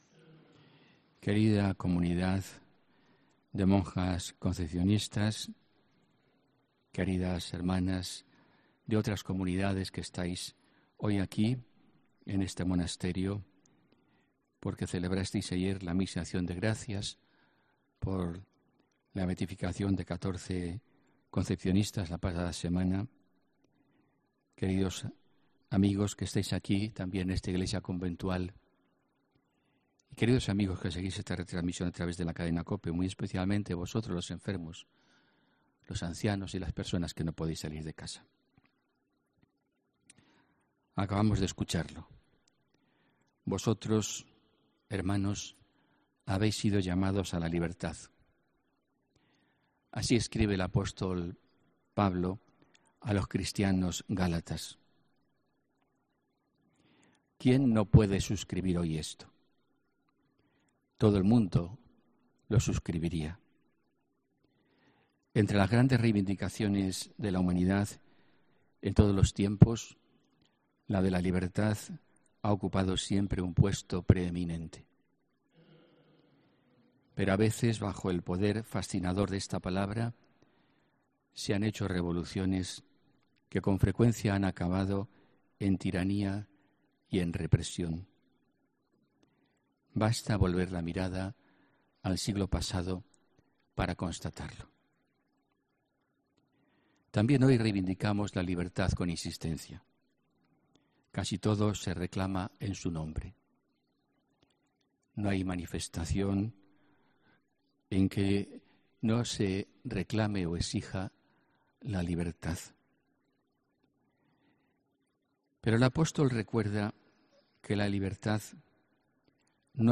HOMILÍA 30 JUNIO 2019